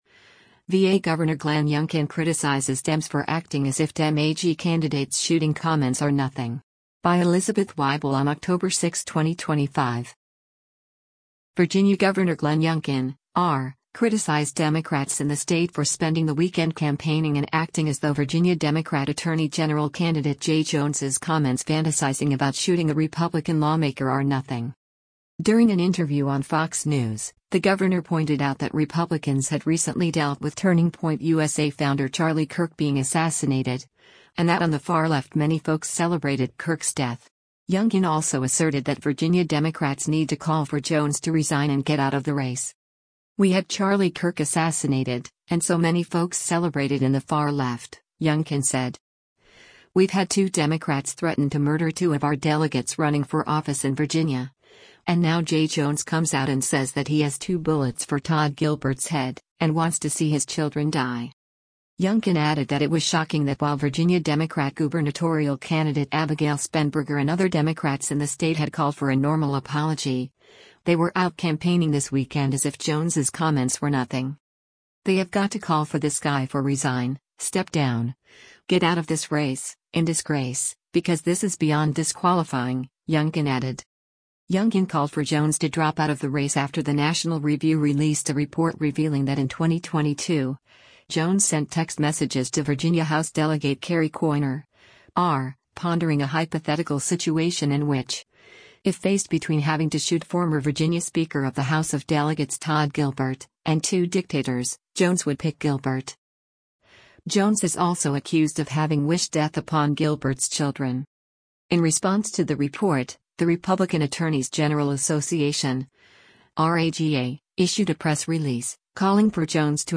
During an interview on Fox News, the governor pointed out that Republicans had recently dealt with Turning Point USA Founder Charlie Kirk being assassinated, and that on the far left “many folks celebrated” Kirk’s death.